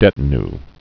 (dĕtn-, -y)